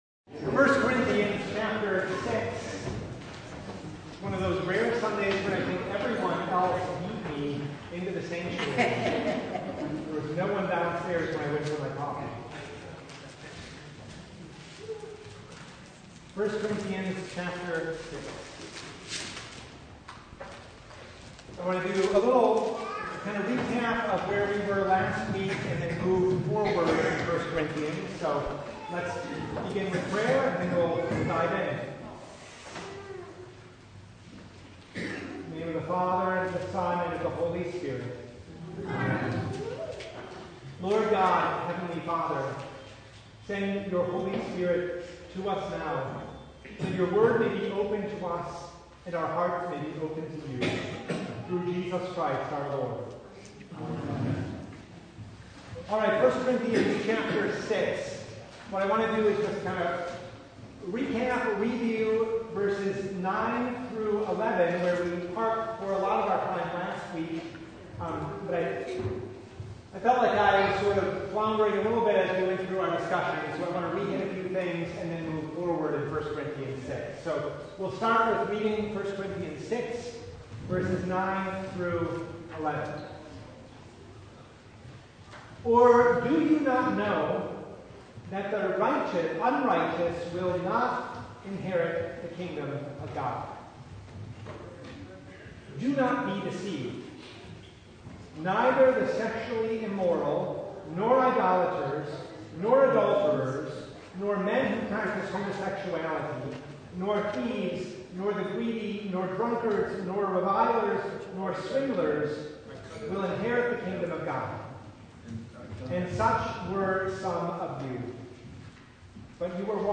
A minor technical problem occurred during the recording of this Bible hour, requiring the insertion of the reading from Ezekiel and a short loss of content following the reading.
Service Type: Bible Hour